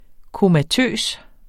Udtale [ komaˈtøˀs ]